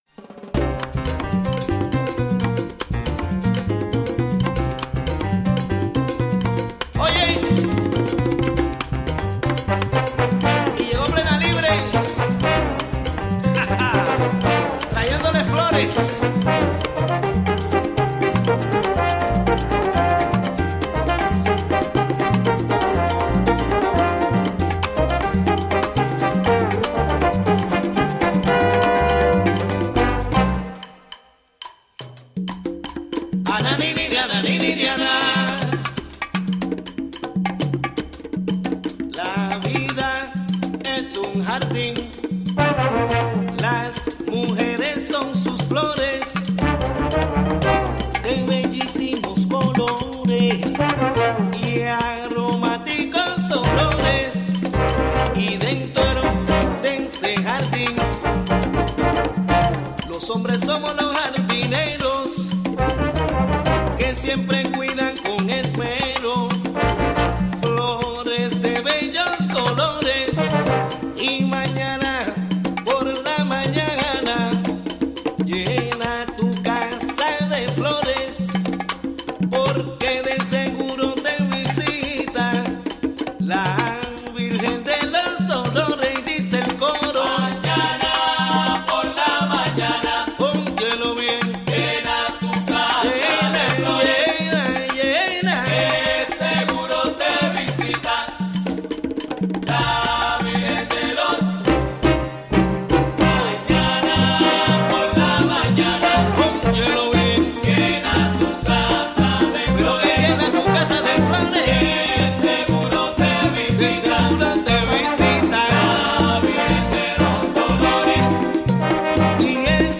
Conversation
interview